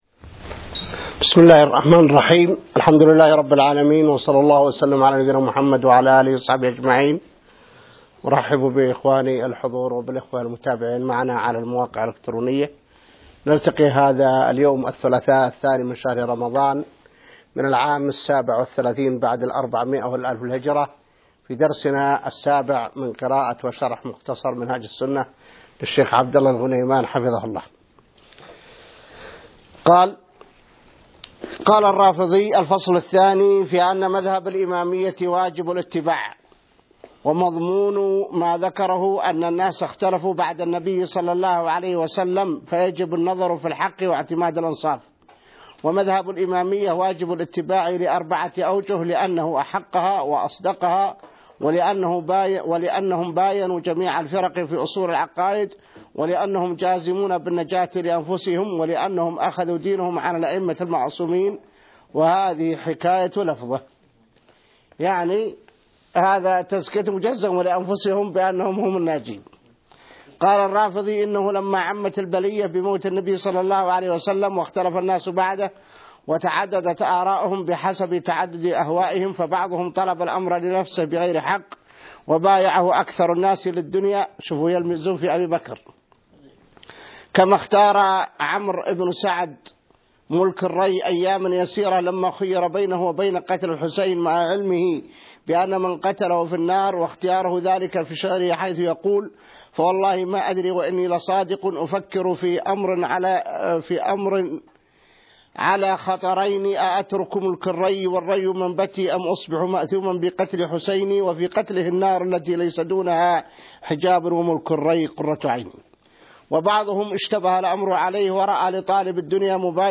الدرس السابع من شرح مختصر منهاج السنة | موقع المسلم